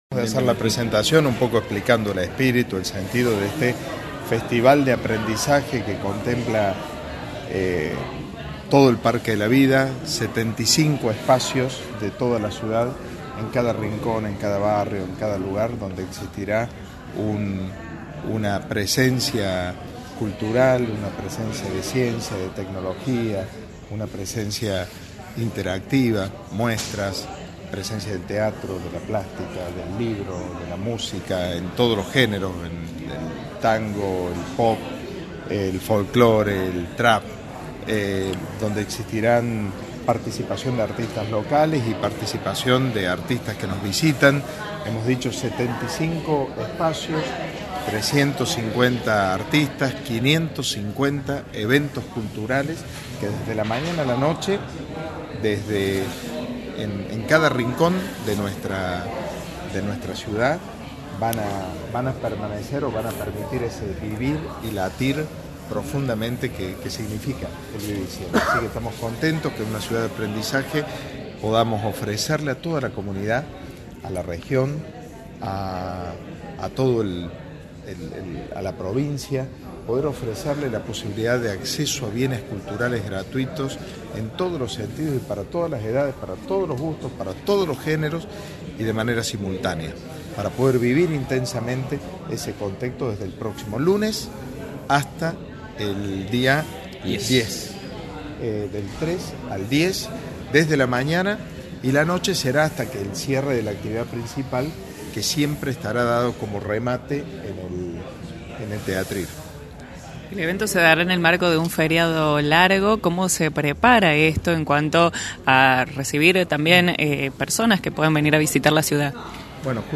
El municipio presentó en una conferencia de prensa, lo que será del 3 al 10 de Octubre el Festival Villa María Vive y Siente que tendrá como escenario el Parque de la Vida donde desfilarán diferentes artistas, como cantantes, actores, figuras del mundo de la cultura.